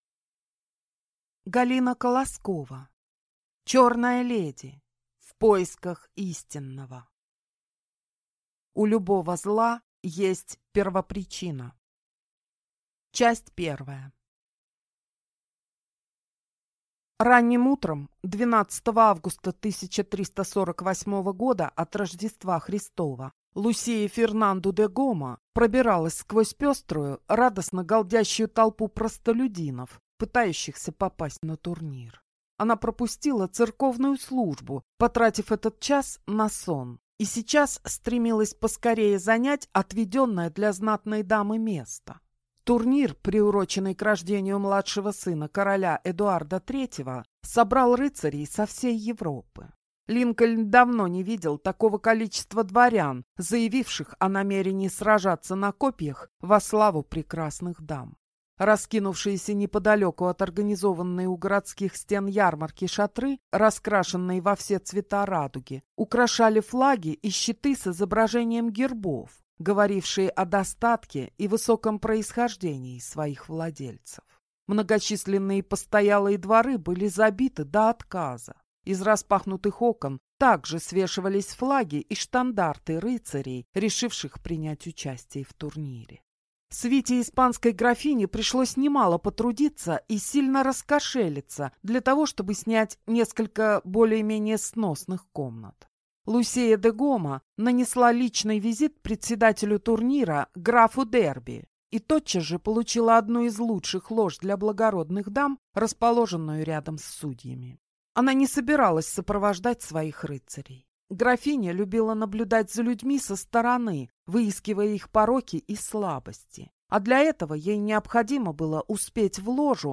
Год 1914-й. Пора отмщения (слушать аудиокнигу бесплатно) - автор Александр Михайловский